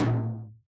Low Tom (New Magic Wand).wav